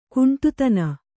♪ kuṇṭutana